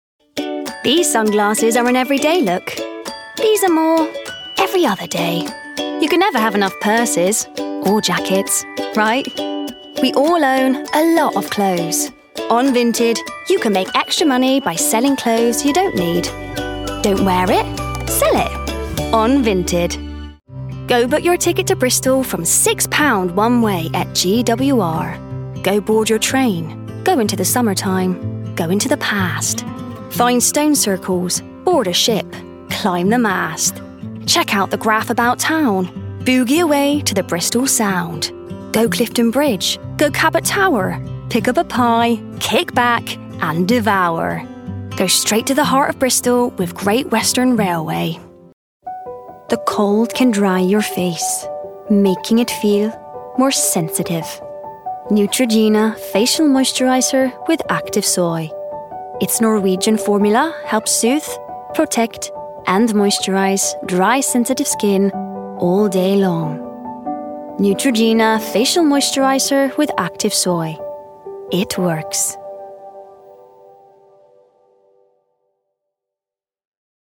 Commercial Showreel
Female
Upbeat